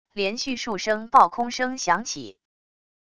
连续数声爆空声响起wav音频